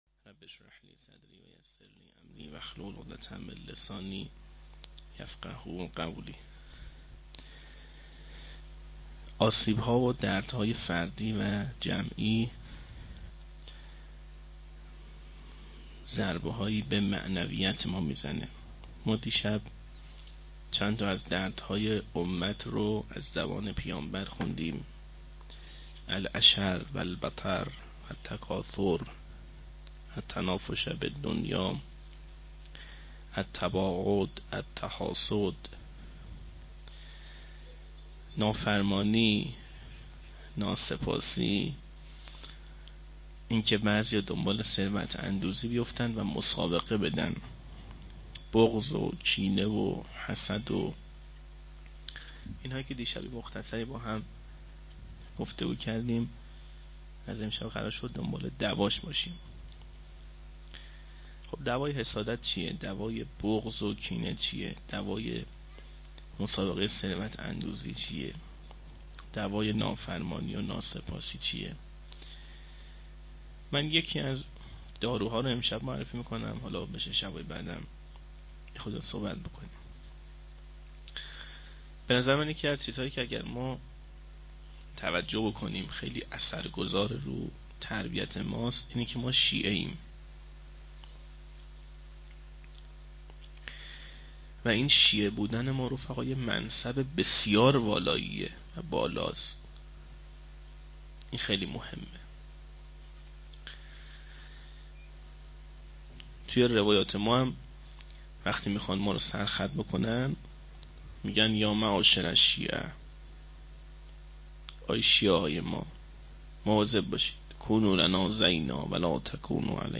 01-shab4-sokhanrani.mp3